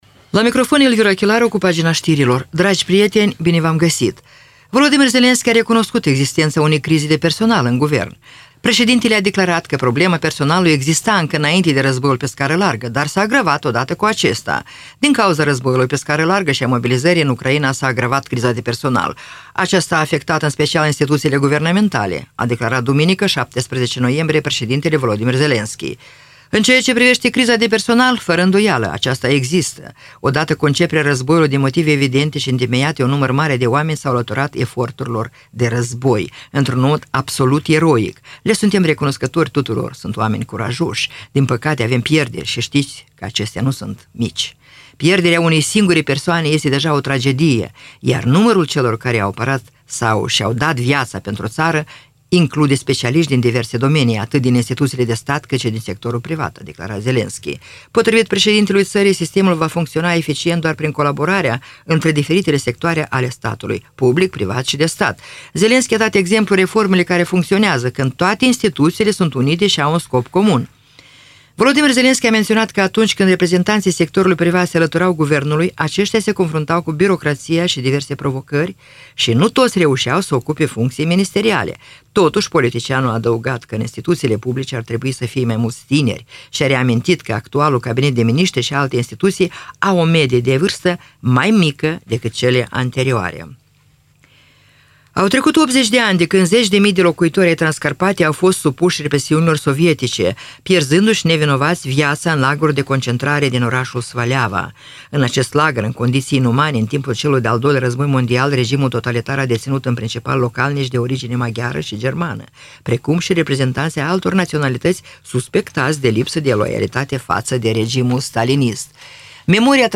Știri Radio Ujgorod – 18.11.2024